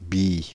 Billy (French pronunciation: [biji]
Fr-Billy.ogg.mp3